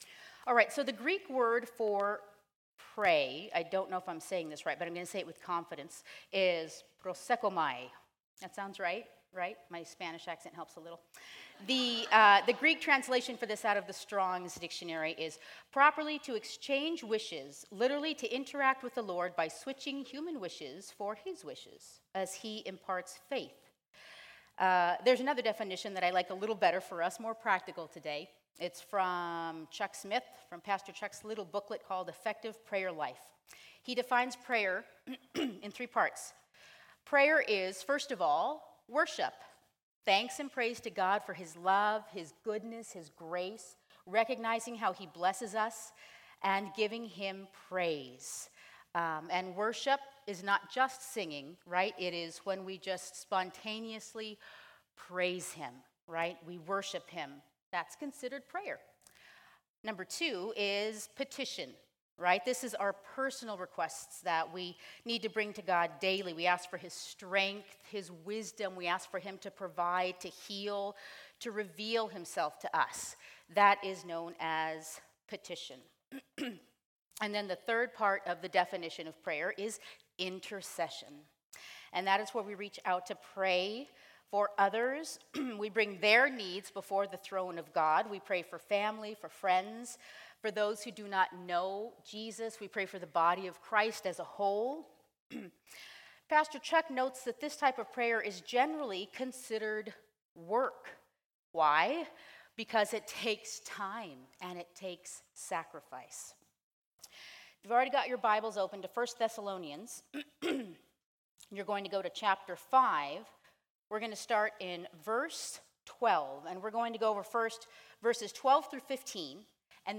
Women's Retreat 2016: My Big Fat Greek Retreat